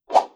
Close Combat Swing Sound 67.wav